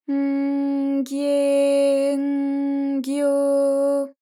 ALYS-DB-001-JPN - First Japanese UTAU vocal library of ALYS.
gy_N_gye_N_gyo_.wav